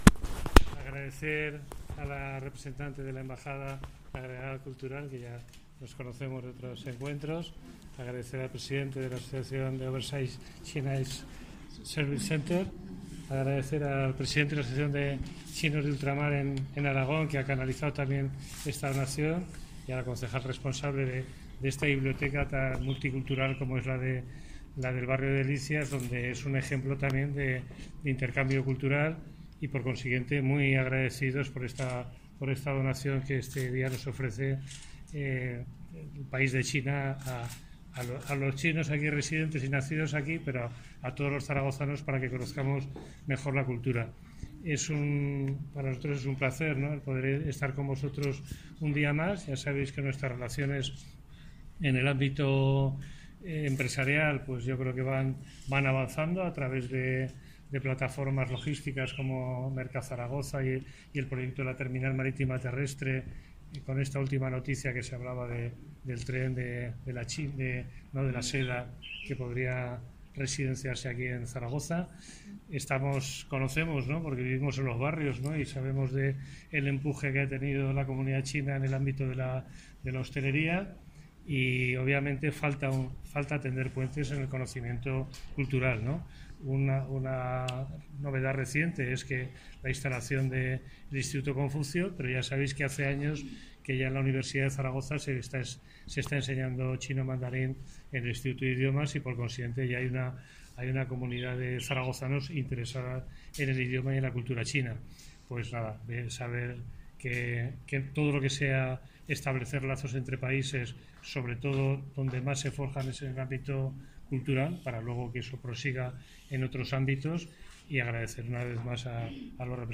DECLARACIONES DEL ALCALDE PEDRO SANTISTEVE